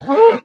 cow_hurt2.ogg